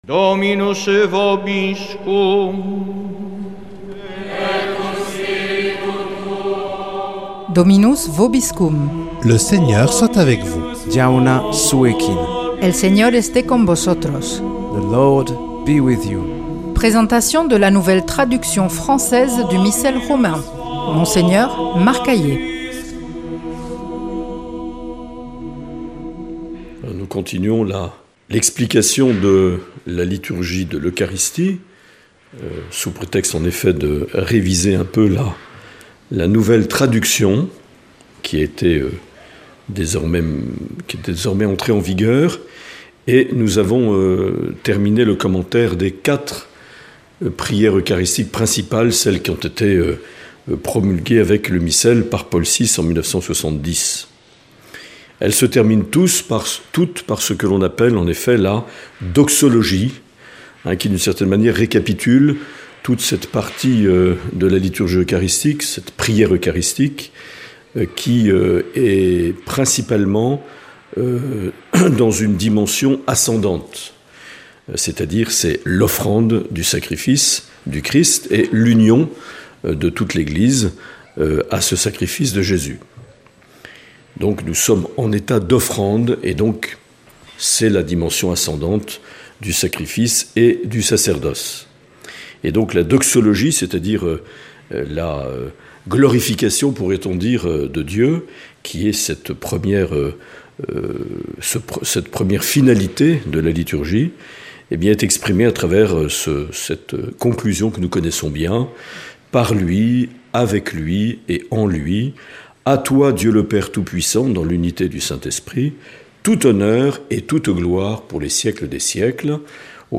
Présentation de la nouvelle traduction française du Missel Romain par Mgr Marc Aillet
Une émission présentée par